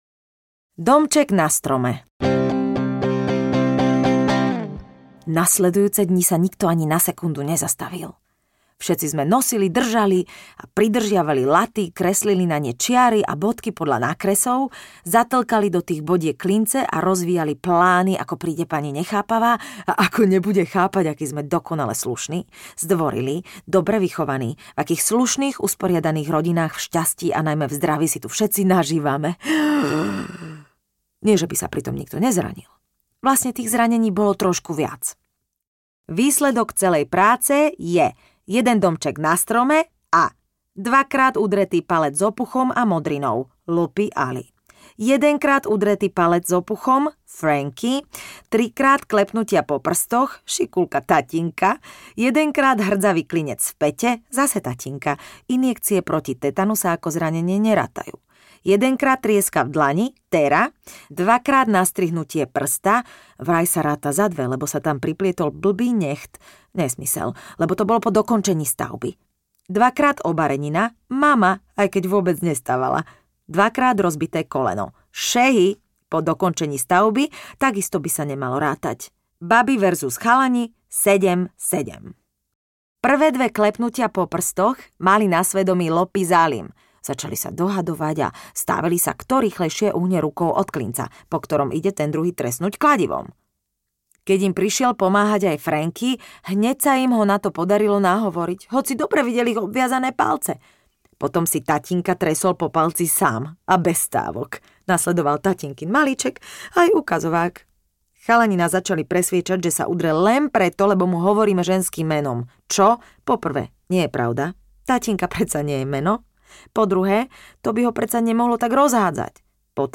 Anča z Pomaranča a tajomný neznámy audiokniha
Ukázka z knihy
• InterpretLucia Siposová